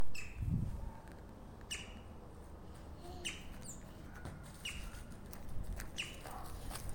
Colaptes melanochloros melanolaimus
English Name: Green-barred Woodpecker
Sex: Both
Life Stage: Adult
Location or protected area: Del Viso
Condition: Wild
Certainty: Observed, Recorded vocal